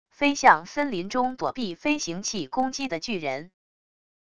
飞向森林中躲避飞行器攻击的巨人wav音频